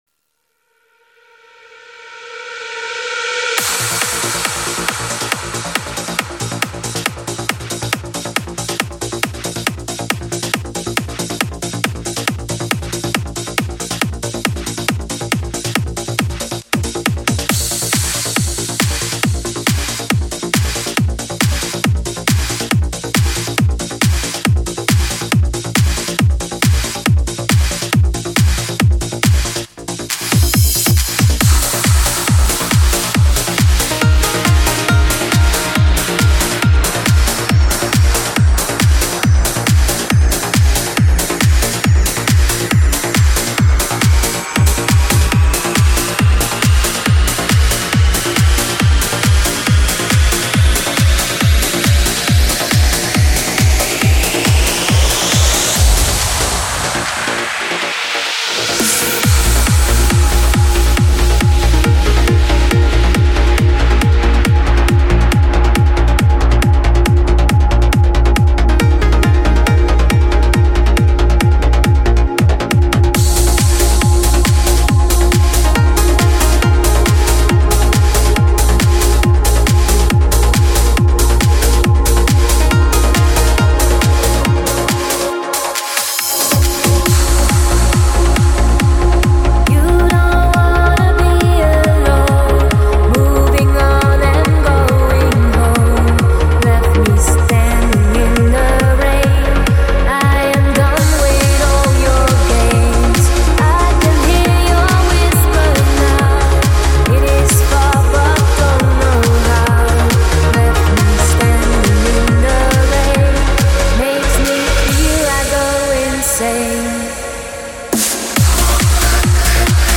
Мега - офигительный Транс!
Подстиль: Vocal Trance / Uplifting Trance